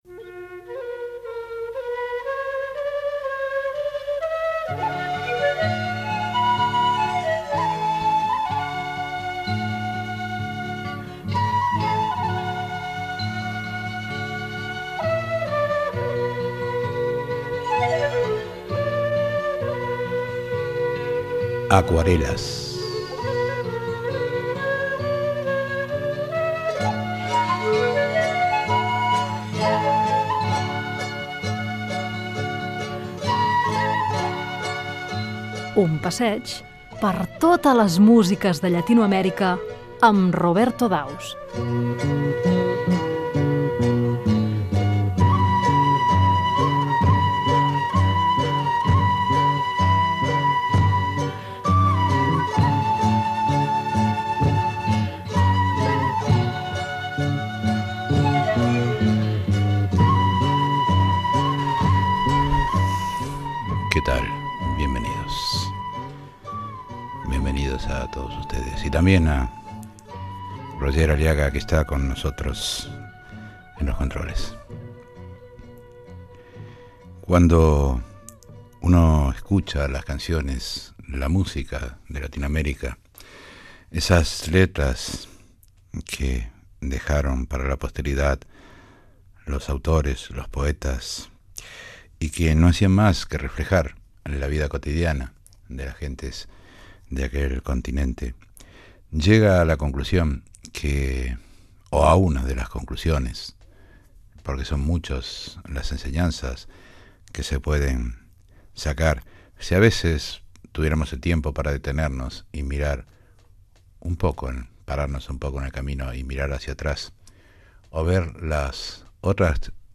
Careta del programa i presentació del programa que fa un recorregut per les músiques de Llatinoamèrica
Musical